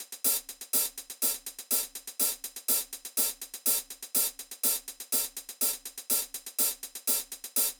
08 Hihat.wav